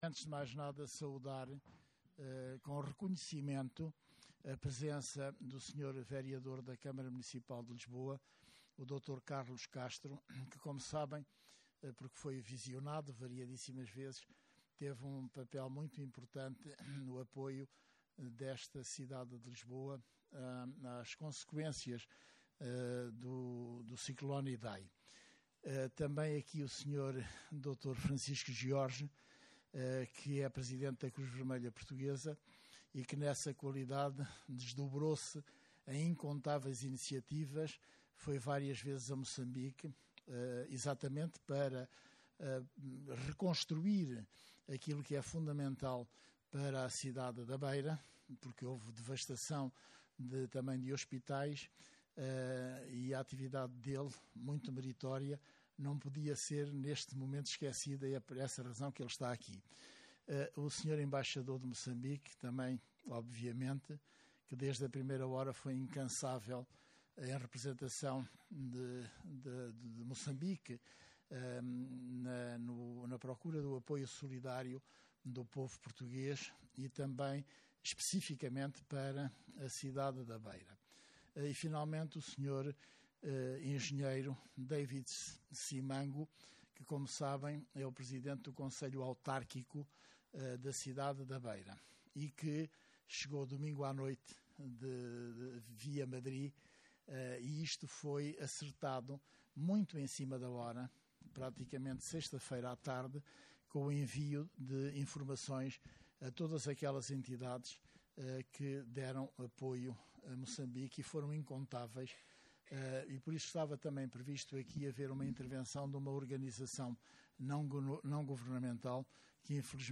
No âmbito do apoio que a cidade da Beira, em Moçambique, recebeu de diversas entidades portuguesas na sequência do ciclone Idai decorreu, dia 28 de janeiro, a apresentação pública da reconstrução e reabilitação da cidade da Beira, num evento que teve lugar no auditório da UCCLA e que contou com a participação do Embaixador de Moçambique em Portugal, Cruz Vermelha Portuguesa, Câmara Municipal de Lisboa, UCCLA e Conselho Autárquico da Beira.